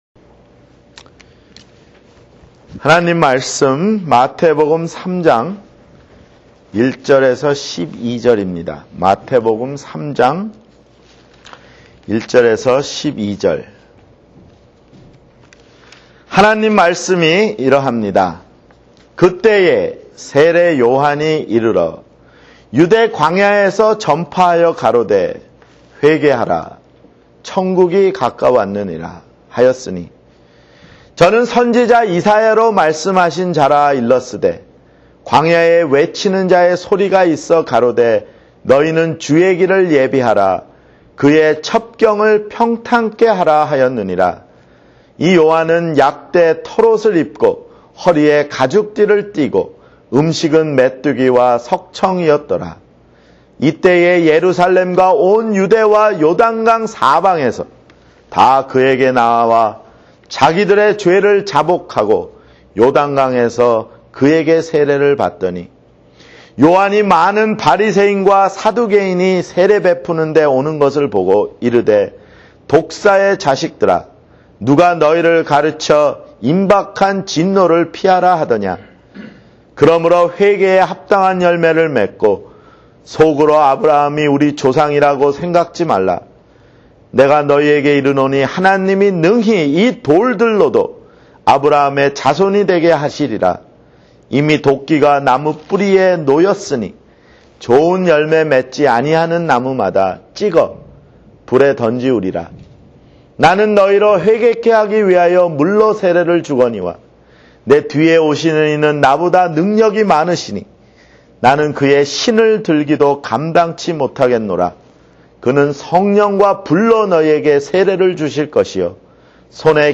[주일설교] 요한복음 6 (3)